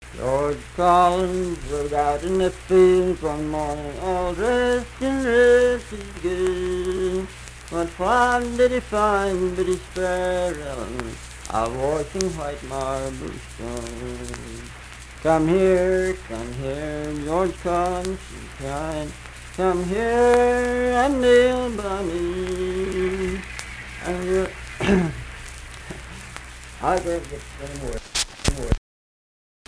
Unaccompanied vocal music
Verse-refrain 1(6).
Voice (sung)